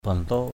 /bʌl˨˩-to:ʔ/ (d.) phụng hoàng, phượng hoàng = phénix mâle. phoenix.